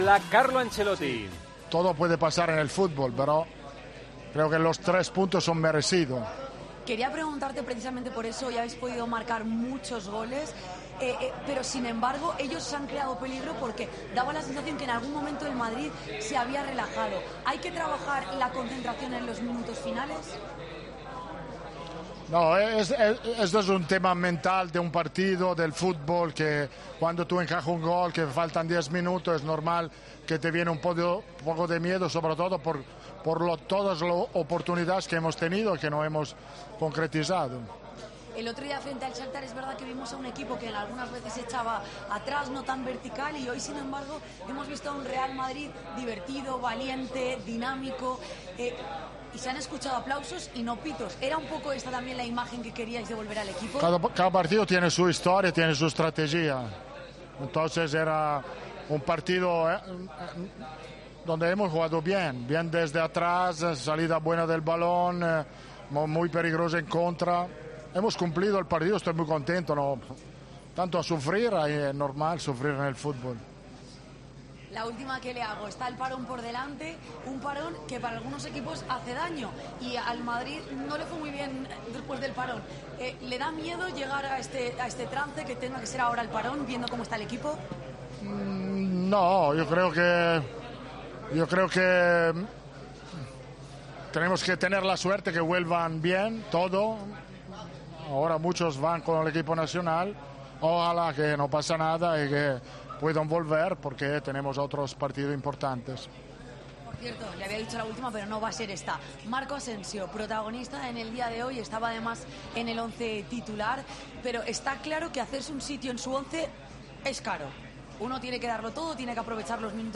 Tras la victoria ante el Rayo